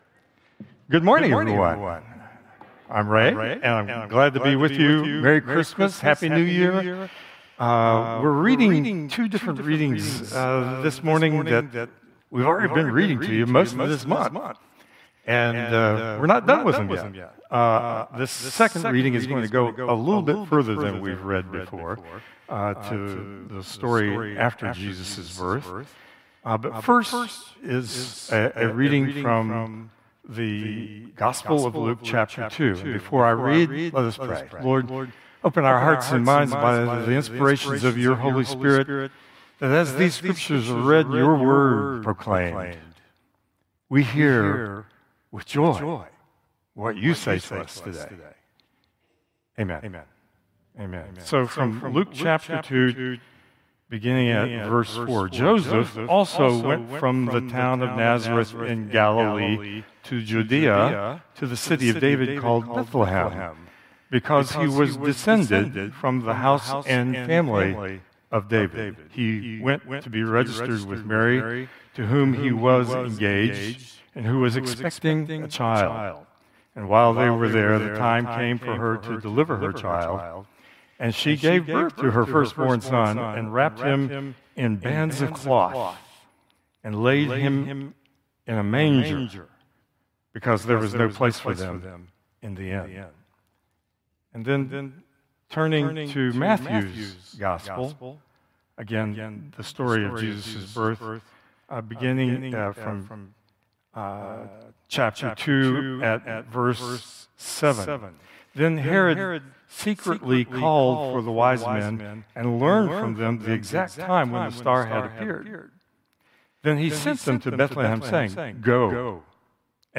Combined Worship Service 12/28/2025